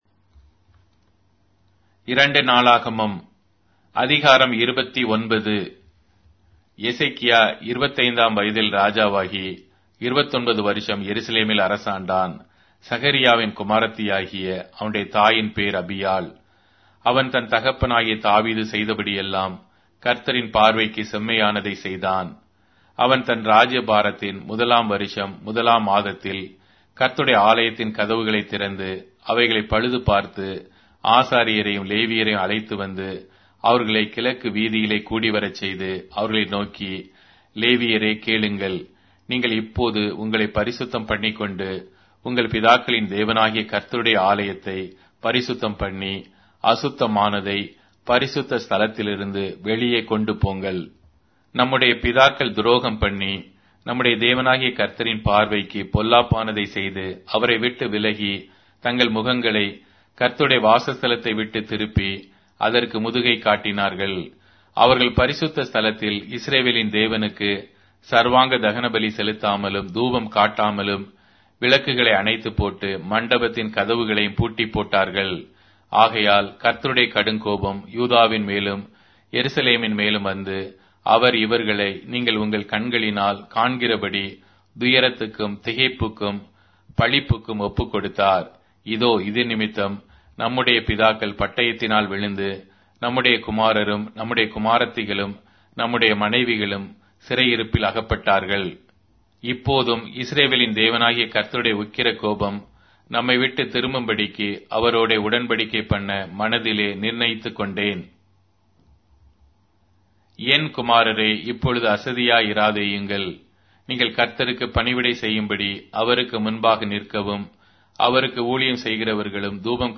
Tamil Audio Bible - 2-Chronicles 6 in Esv bible version